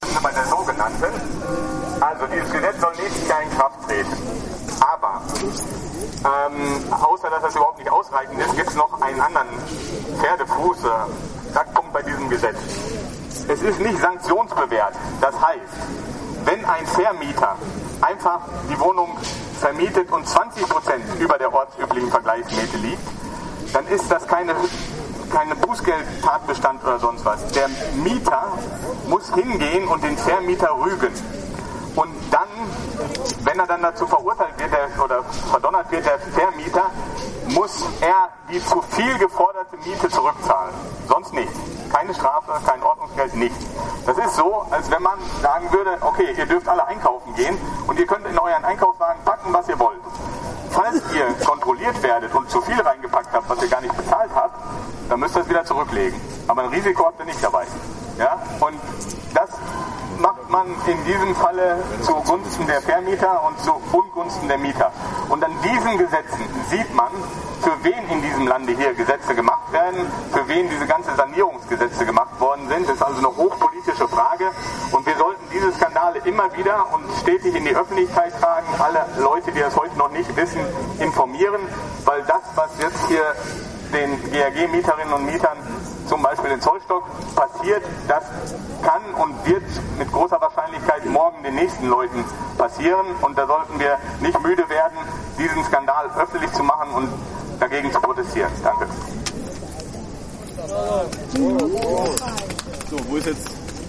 Kurz vor der Ratssitzung am 2. September gingen betroffene Kölner Mieter vor dem Rathaus in Stellung, um auf ihre Wohnsituation aufmerksam zu machen.